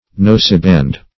Noseband \Nose"band`\, n. That part of the headstall of a bridle which passes over a horse's nose.